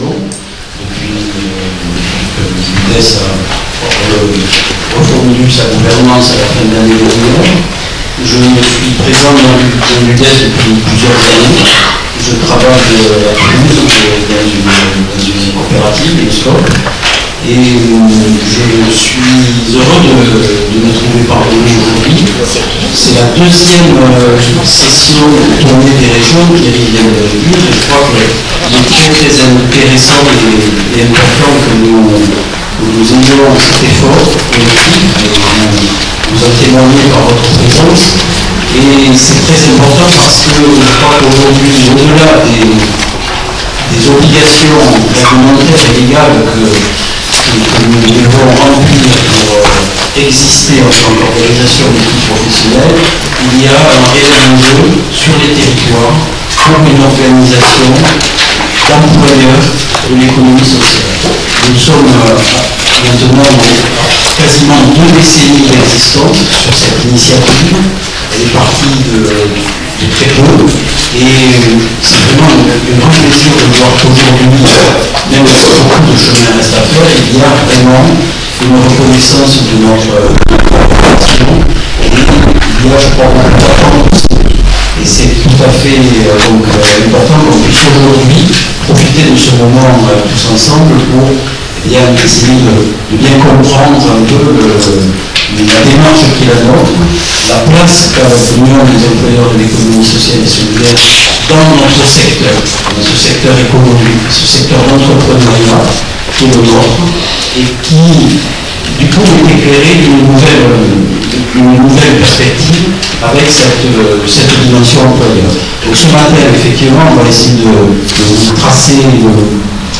JOURNEE REGIONALE UNION DES EMPLOYEURS DE L’ECONOMIE SOCIALE ET SOLIDAIRE (UDES) 04/10/16